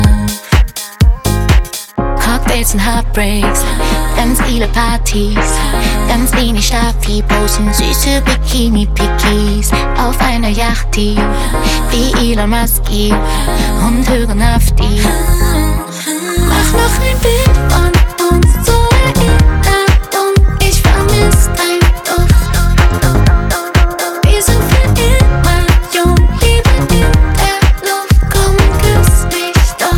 Жанр: Иностранный рэп и хип-хоп / Рэп и хип-хоп